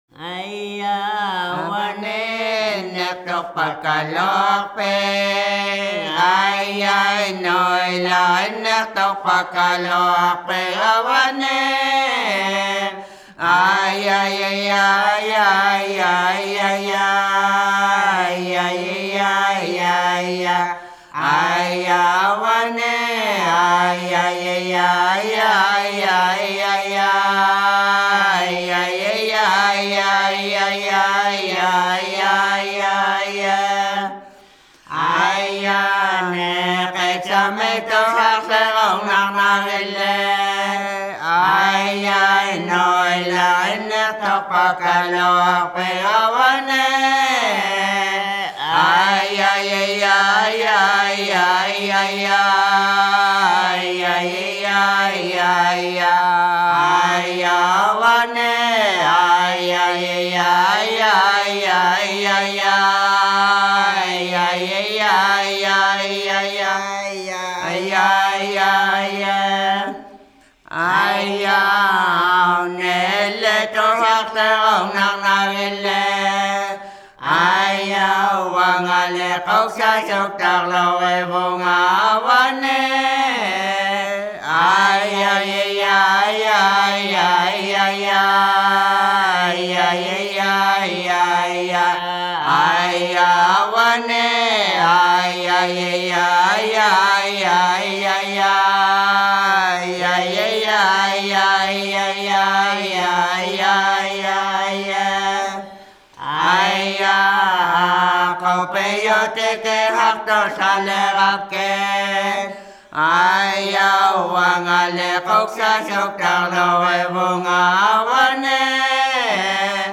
Шаманские песнопения в технике северного горлового пения.
Никаких обработок, никаких сэмплов - чистая аутентика!